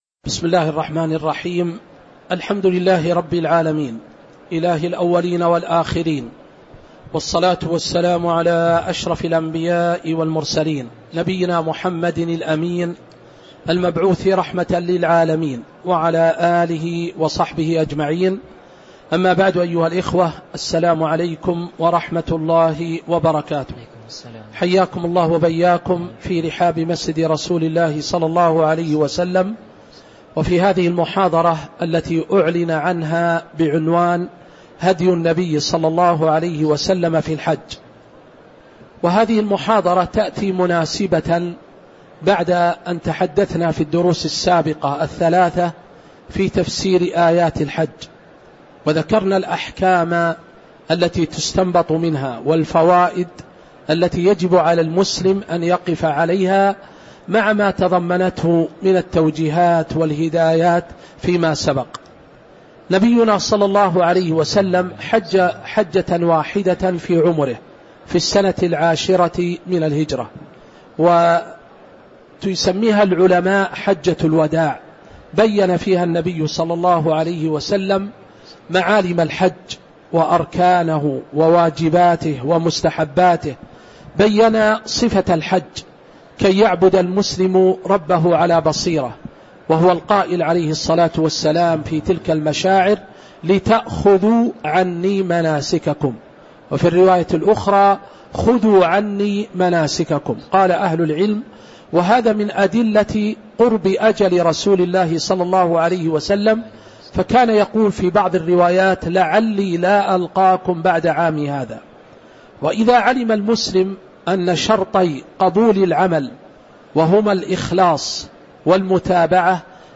تاريخ النشر ٢٧ ذو القعدة ١٤٤٦ هـ المكان: المسجد النبوي الشيخ